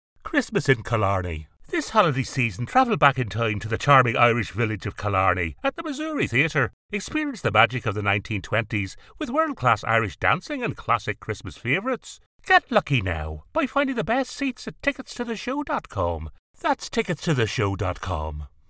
Male
Storytelling ,Baritone , Masculine , Versatile and Thoughtful . Commercial to Corporate , Conversational to Announcer . I have a deep, versatile, powerful voice, My voice can be thoughtful , authoritative and animated . Confident and able to deliver with energy , humorous upbeat and Distinctive . Animated
Radio Commercials
Southern Irish Upbeat Com